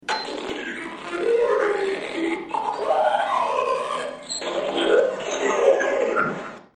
File:Android Activation Quiet.mp3
Android_Activation_Quiet.mp3